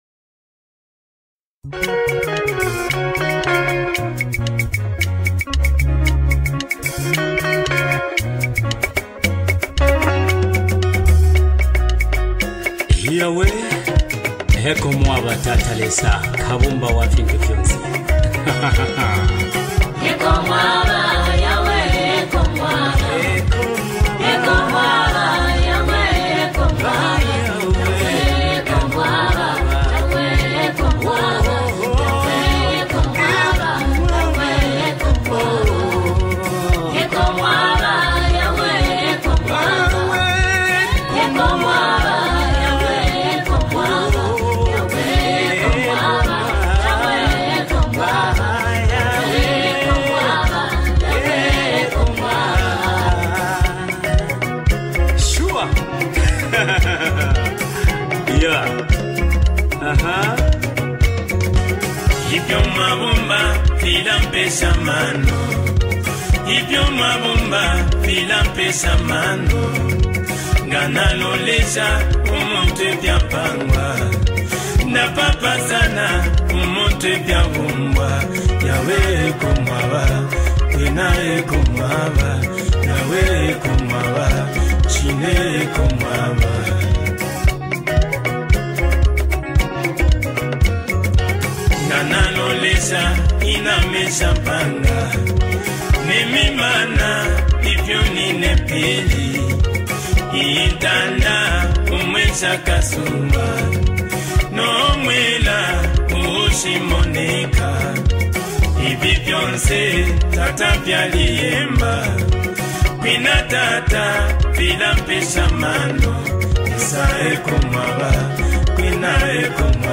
a soulful, heartfelt worship experience
With its smooth, rhythmic beats
rich vocals